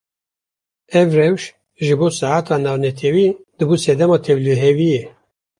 Pronúnciase como (IPA)
/rɛwʃ/